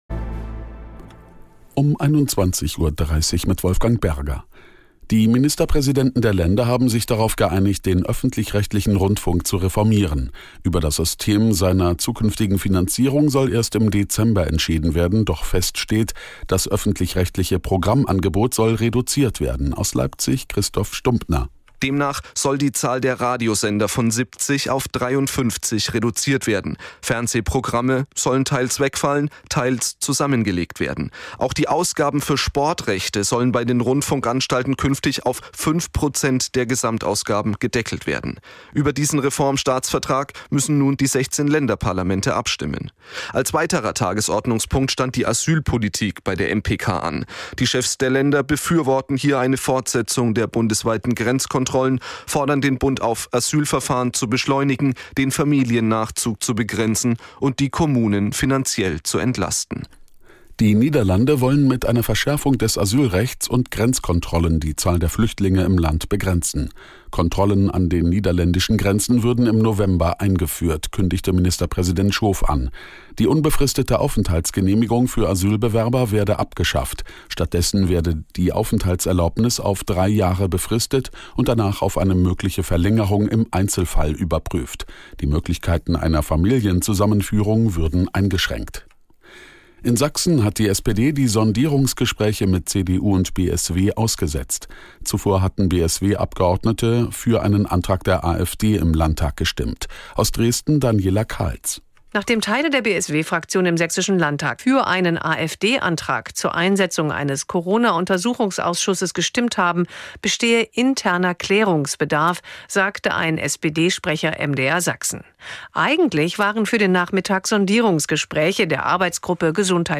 Nachrichten - 25.10.2024